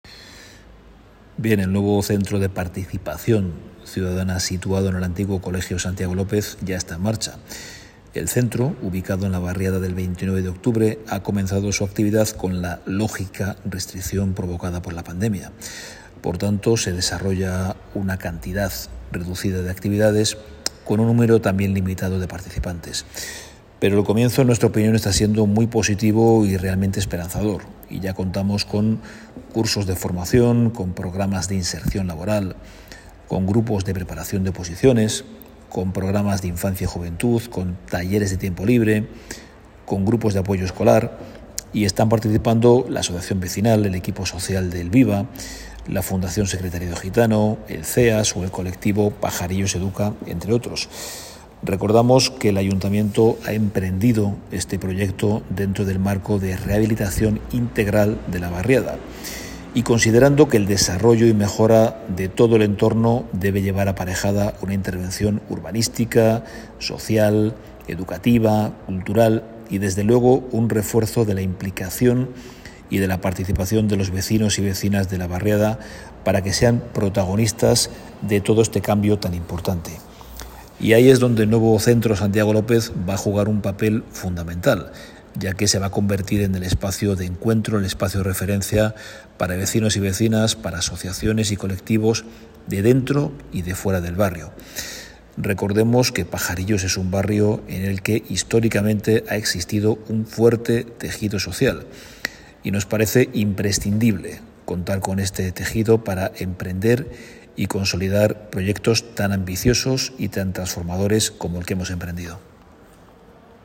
declaraciones concejal Alberyo Bustos visita al Santiago López